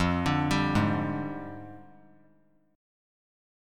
Fm6add9 Chord
Listen to Fm6add9 strummed